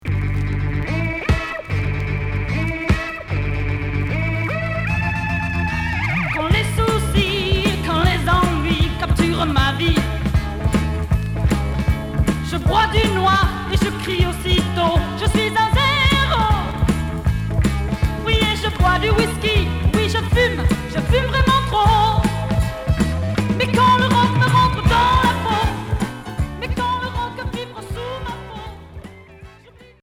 Rockabilly Troisième 45t retour à l'accueil